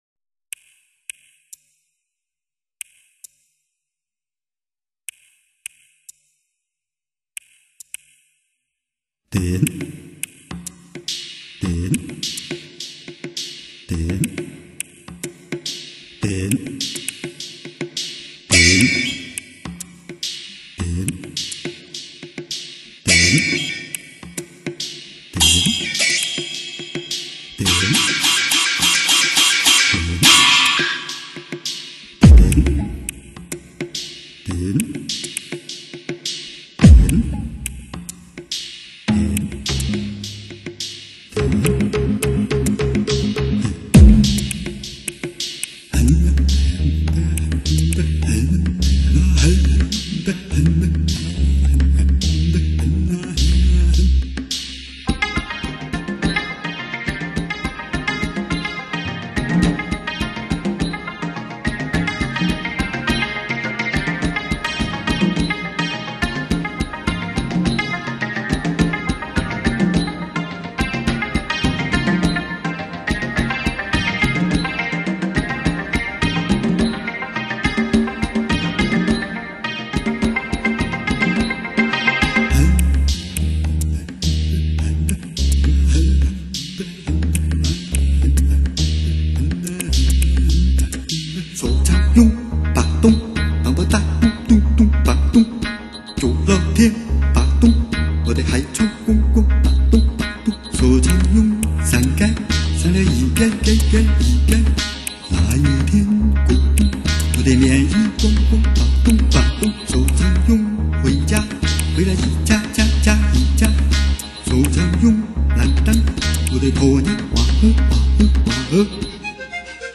呵呵，小子也不才，个人认为应该属于世界音乐的范畴，是有很传统的中国特色的世界音乐。